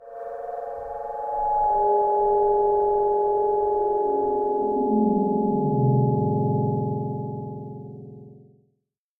Minecraft / ambient / cave / cave5.ogg
should be correct audio levels.